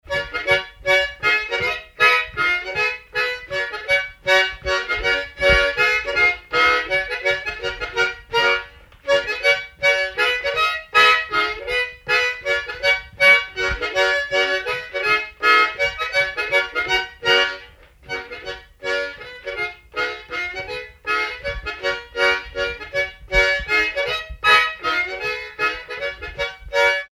Mazurka
danse : mazurka
circonstance : bal, dancerie
Pièce musicale inédite